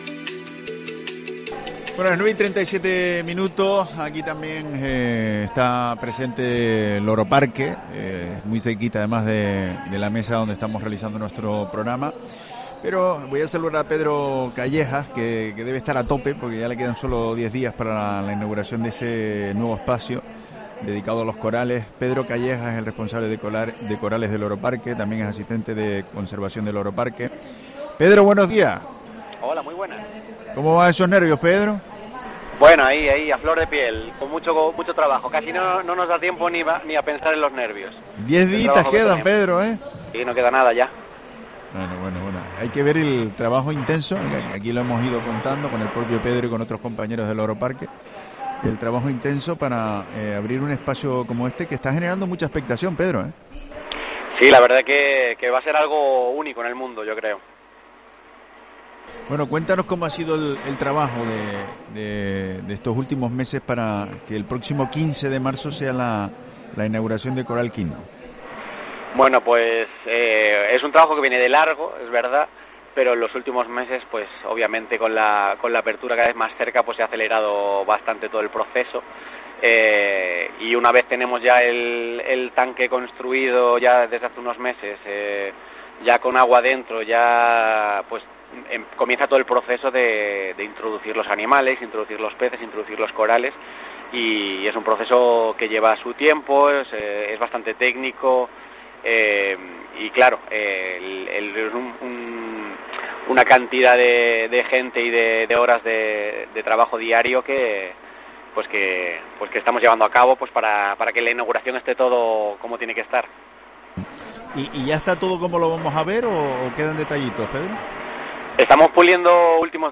En una entrevista en Herrera en Canarias